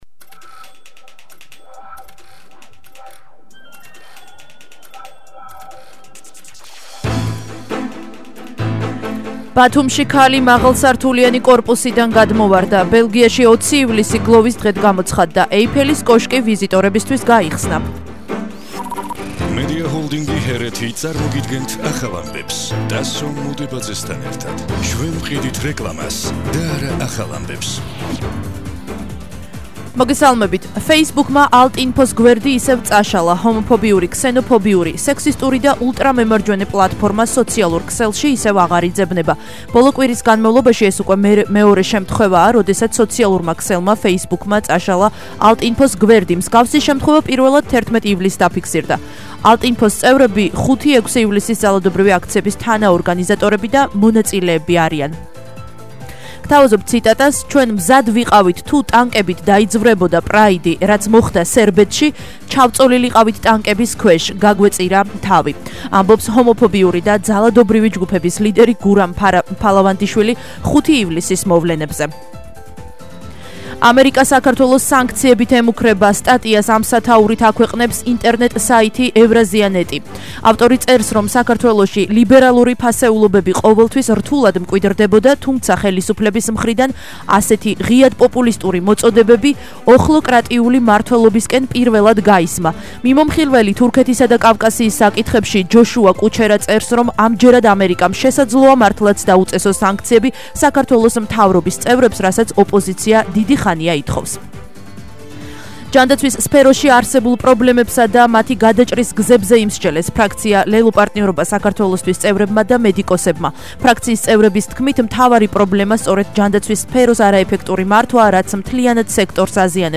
ახალი ამბები 20:00 საათზე –16/07/21 - HeretiFM